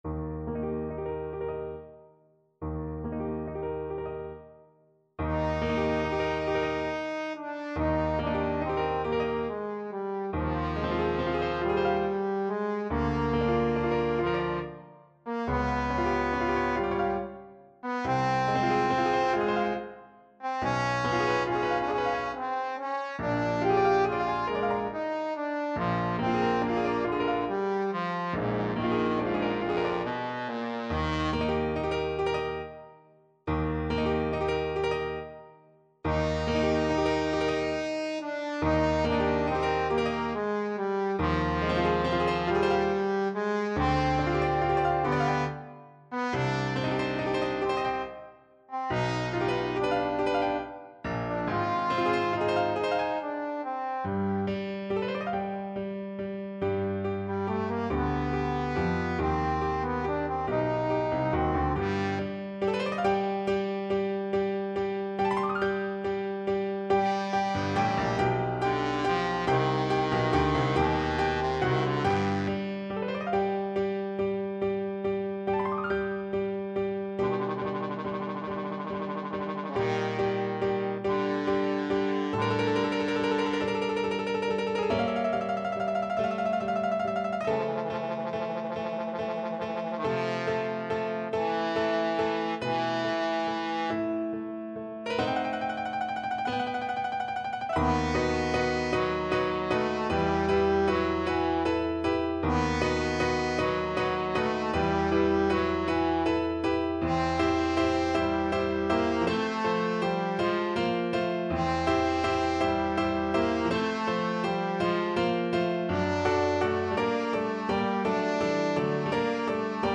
Trombone
Eb major (Sounding Pitch) (View more Eb major Music for Trombone )
12/8 (View more 12/8 Music)
Adagio maestoso
Classical (View more Classical Trombone Music)